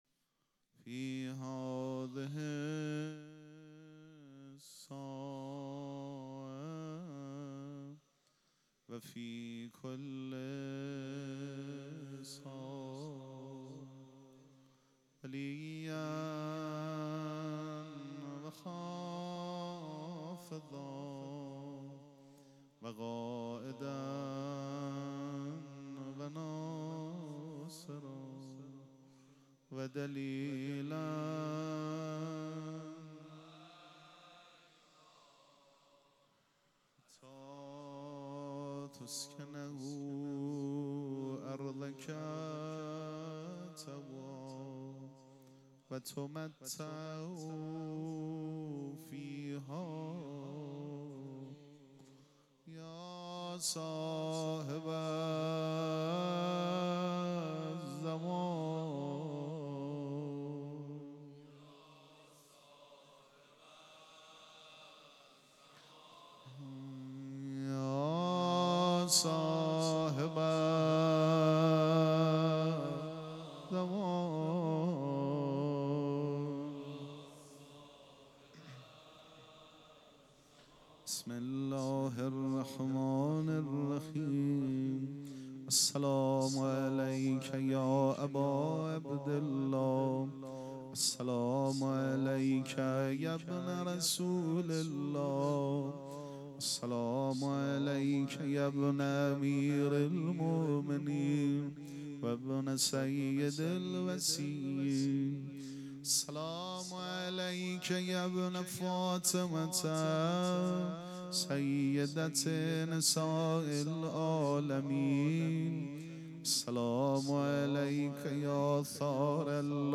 مراسم عزاداری شب نهم محرم الحرام ۱۴۴۷
پیش منبر